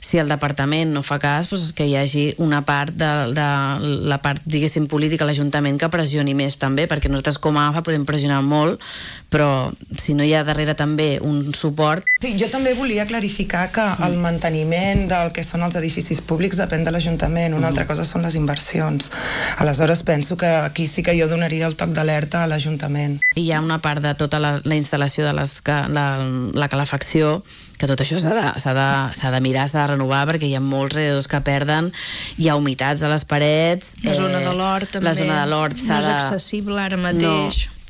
han passat aquest dilluns pel matinal de RCT per compartir la seva experiència després de dos mesos de curs.